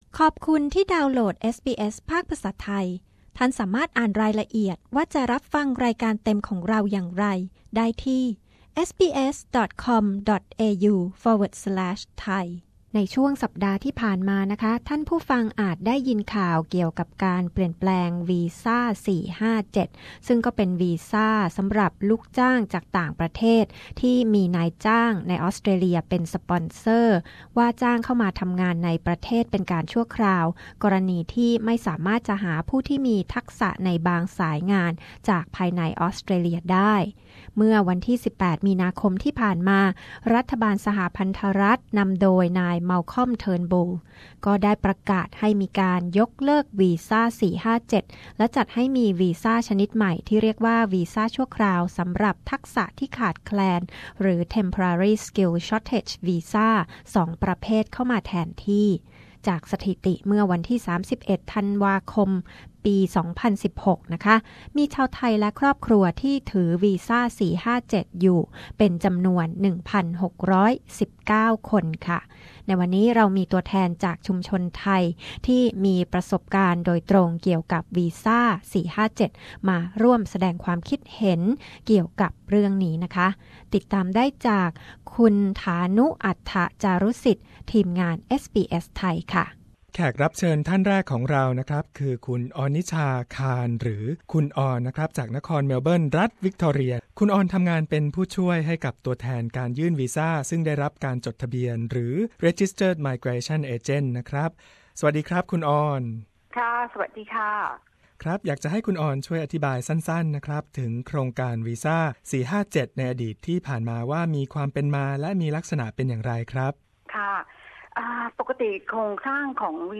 ชุมชนไทยจะได้รับผลอย่างไรต่อการที่รัฐบาลสหพันธรัฐของออสเตรเลียประกาศยกเลิกวีซ่า 457 เอสบีเอส ไทย คุยกับตัวแทนคนไทยที่มีประสบการณ์ตรงเรื่องนี้ ทั้งตัวแทนยื่นวีซ่า นายจ้าง และลูกจ้างที่ถือวีซ่า 457